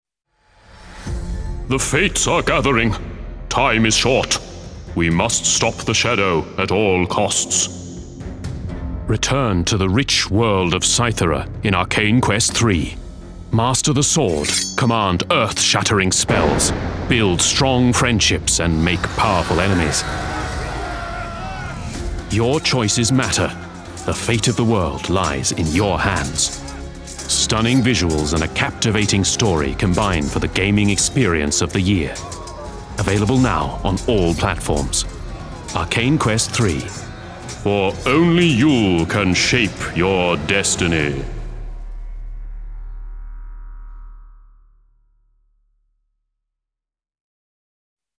Authentic  |  Warm  |  Versatile
Commercial: Gaming